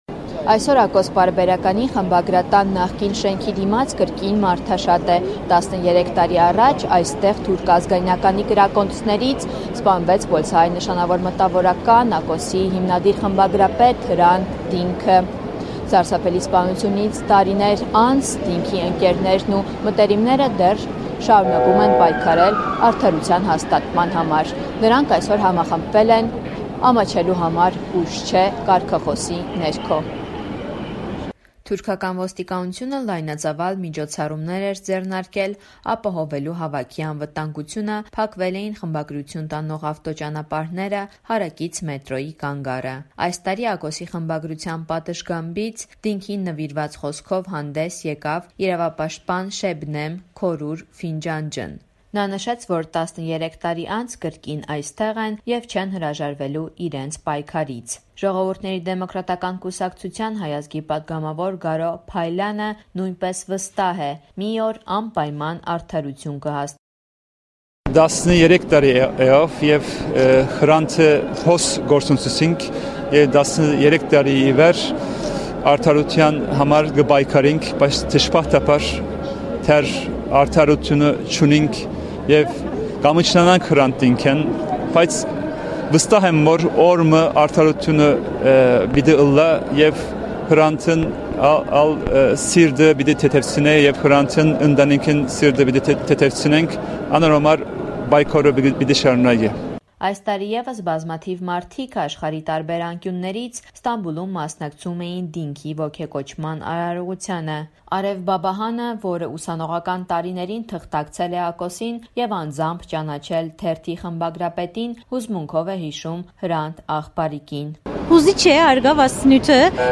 Ստամբուլում տեղի ունեցավ Հրանտ Դինքի սպանության 13-րդ տարելիցին նվիրված հիշատակի միջոցառում
Ռեպորտաժներ